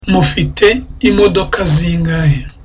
(Gladly)